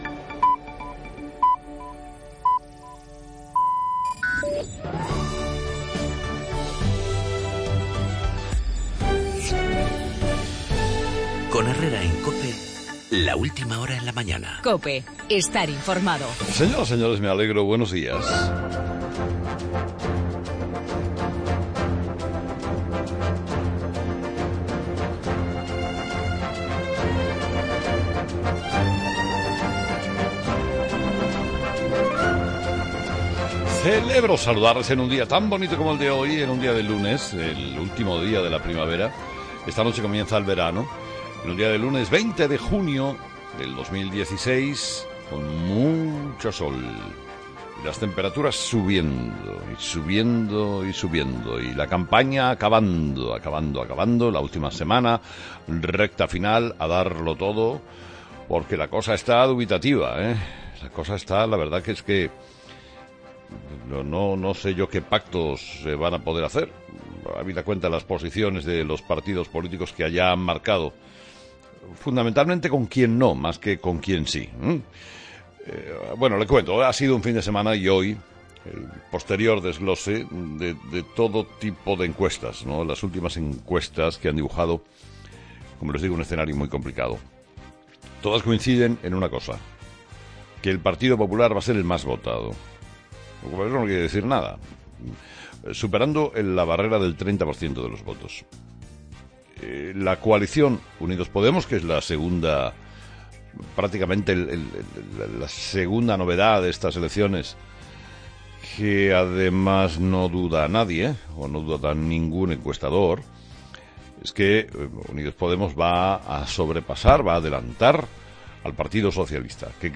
Las encuestras publicadas en la prensa este domingo de cara al próximo 26-J, con Unidos-Podemos como segunda fuerza y con "la debacle" del PSOE, que tendrá "la varita mágica" para decidir quién puede gobernar tras el 26-J; el 'brexit' en Reino Unido con las encuestras igualadas; y la polémica del vídeo que acusa de racista a Pedro Sánchez por limpiarse las manos tras darle la mano a un niño de raza negra, una polémica "de una bajeza" ínfima, "en política no todo vale, en el editorial de Carlos Herrera a las 8 de la mañana.